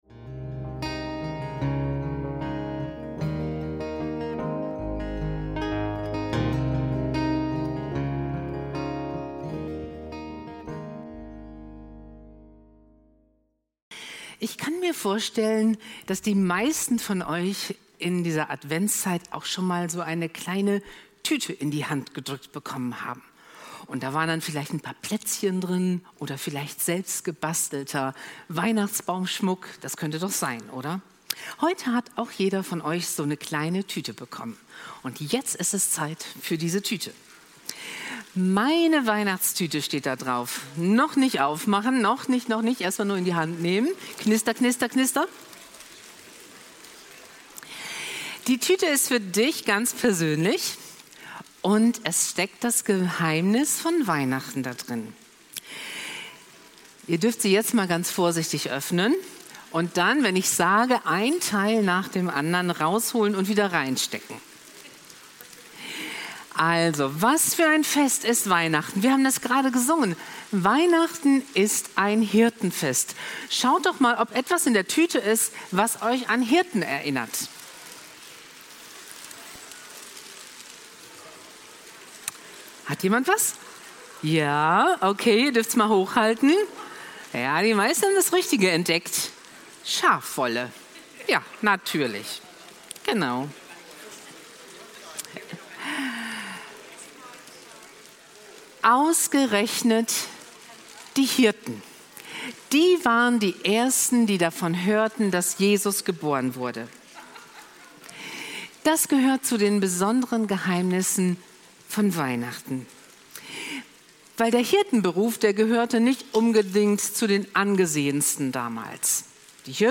Weihnachten das ___Fest - Predigt von Heiligabend 2025